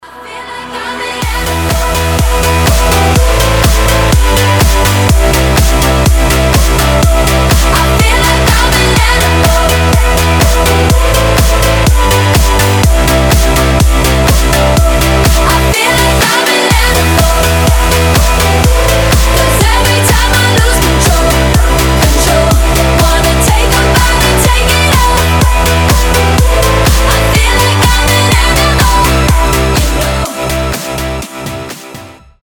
• Качество: 320, Stereo
громкие
жесткие
мощные
Electronic
EDM
взрывные
энергичные
progressive house